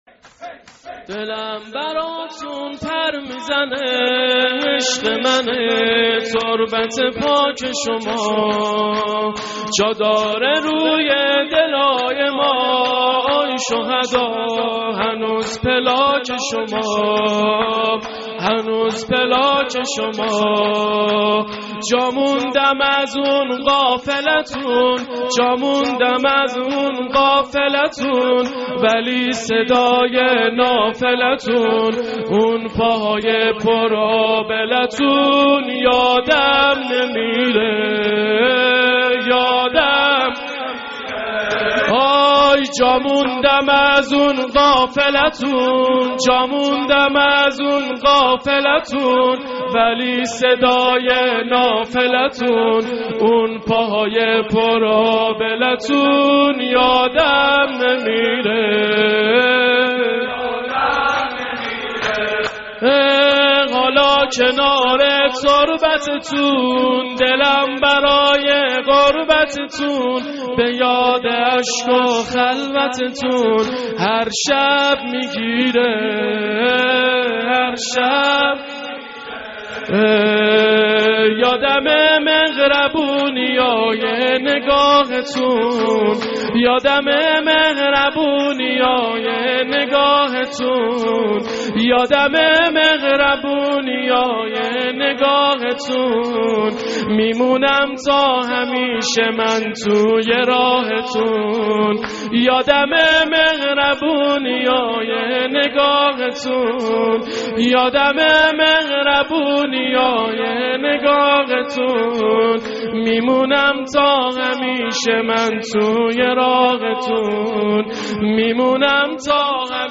maddahi-242.mp3